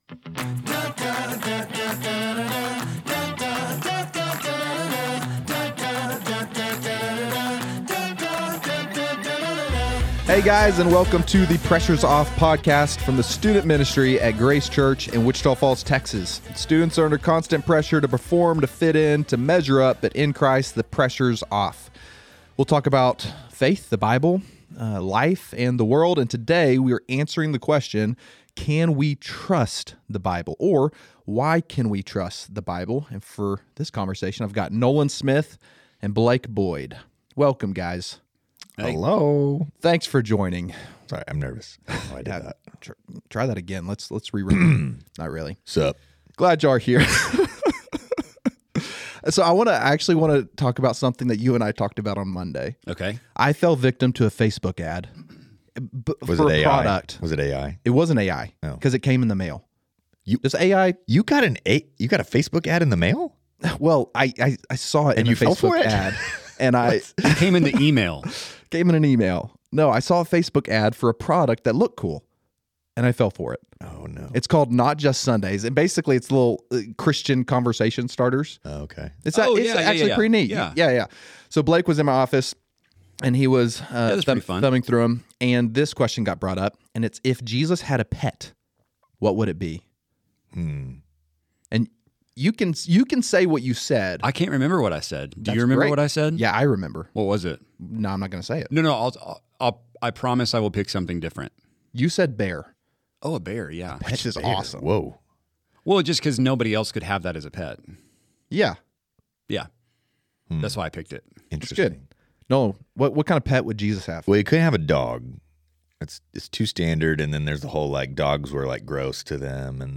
Student leaders and guests sit down to discuss life, theology, and the Bible to help students know and follow Jesus at a deeper level.